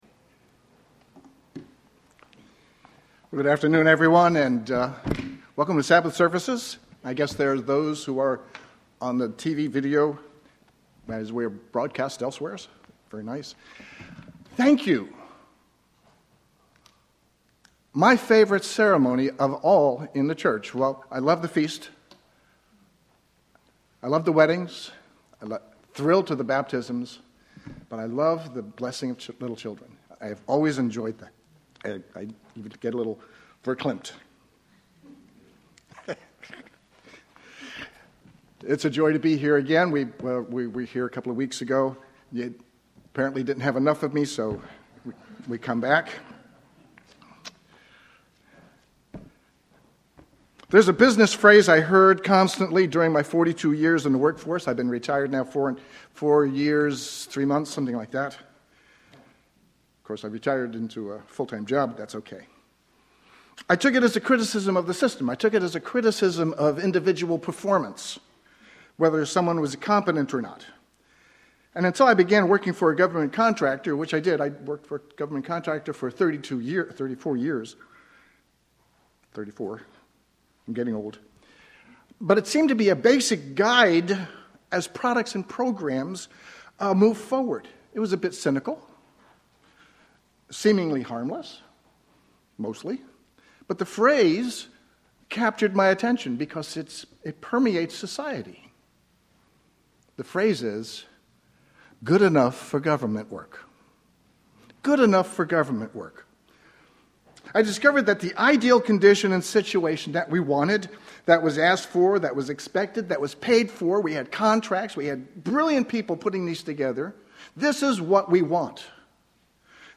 Sermons
Given in Sacramento, CA